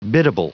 Prononciation du mot biddable en anglais (fichier audio)
Prononciation du mot : biddable